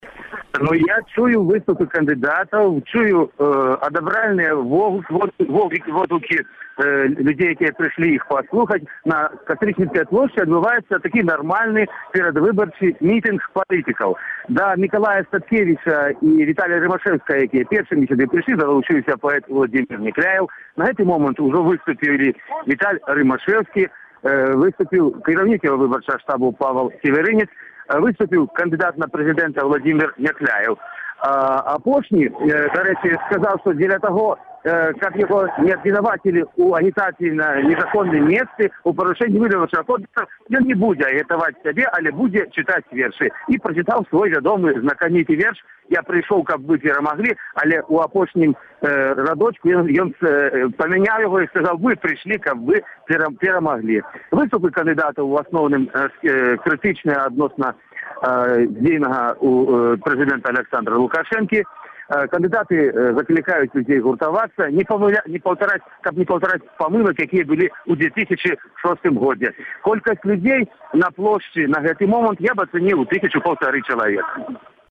перадае з Кастрычніцкай плошчы